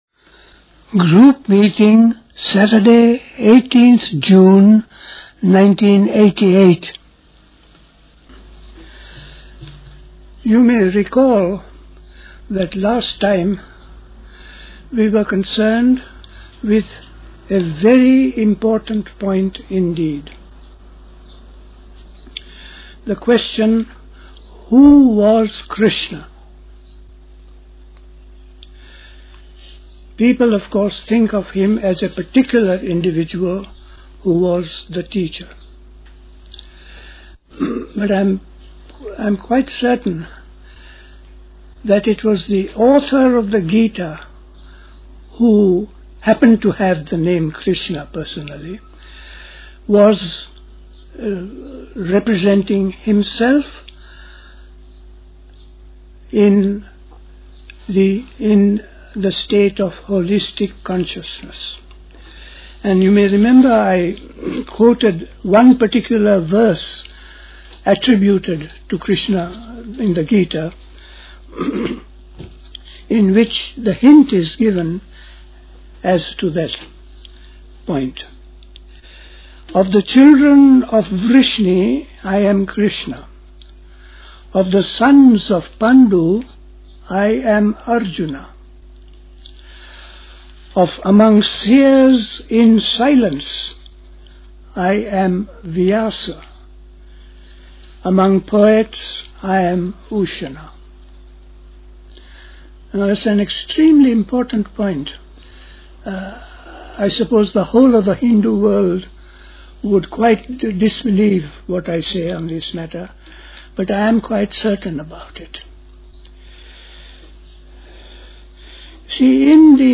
The Dilkusha Talks